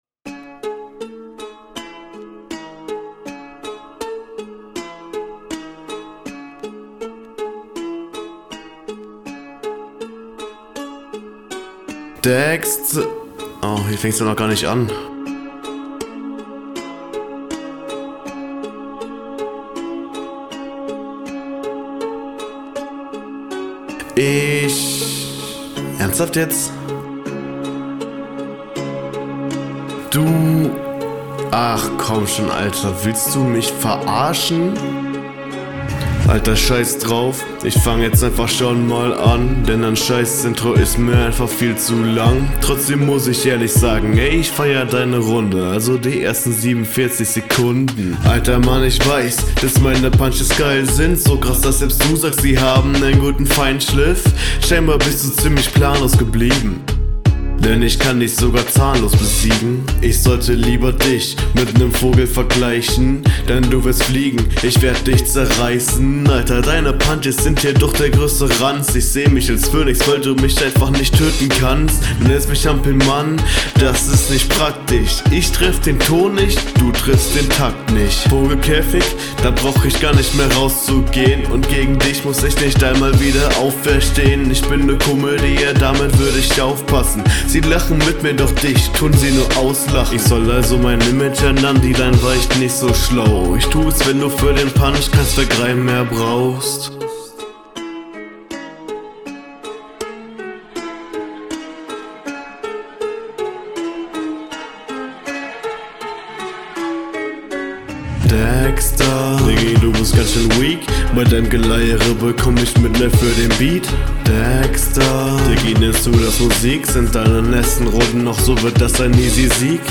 Klingst viel routinierter und übersichtlicher als dein Gegner.